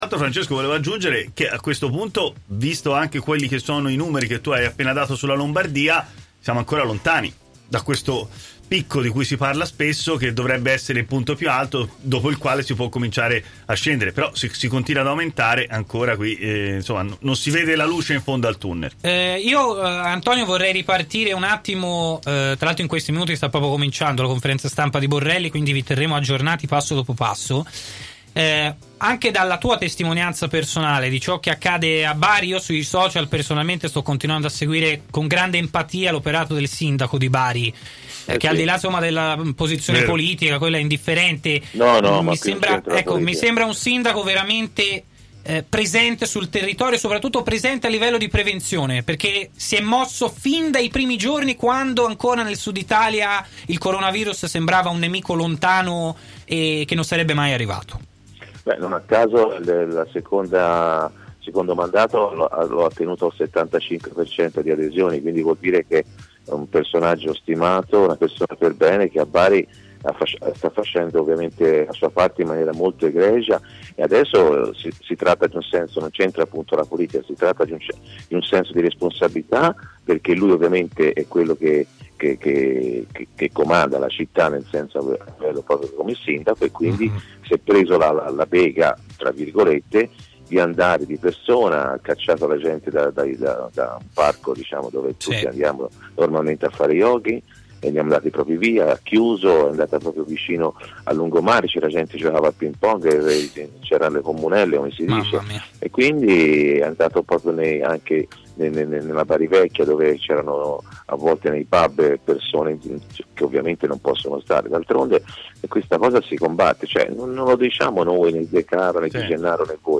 L’opinionista Antonio Di Gennaro interviene a “Stadio Aperto” su TMW Radio per parlare della situazione Coronavirus che tiene sotto scacco l’Italia.